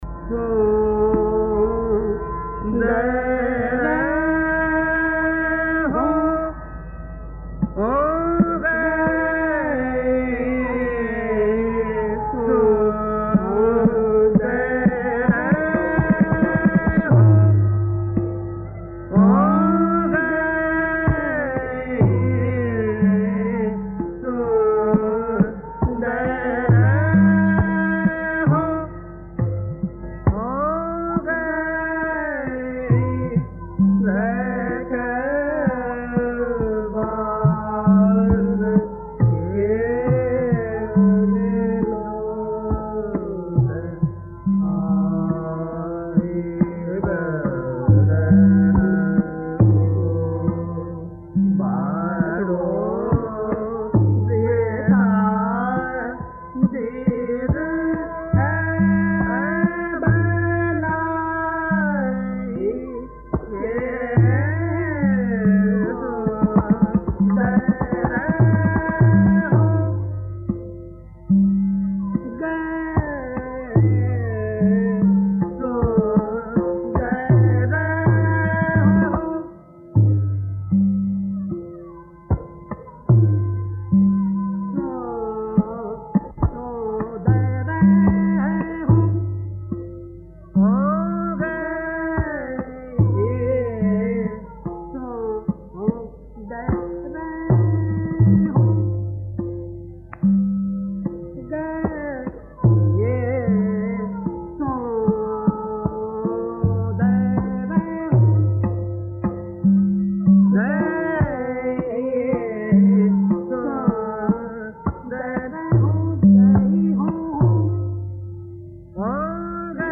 Dhrupad | Moinuddin Khan
His style was characterised by a certain flamboyance, and there were always surprises in the sudden unexpected turns that his melodic improvisation could take.
He had amazing mastery over the use of the three different kinds of head resonance- nasika, anunasika and nirrannasika, which he used with much flair and artistry on the higher notes like ni and sa.
Here are recordings of Moinuddin and Aminuddin Dagar singing raga desi and in a live broadcast from a music conference raga bihag with Ahmedjan Thirakwan on tabla
raga bihag tabla Ahmedjan Thirakwan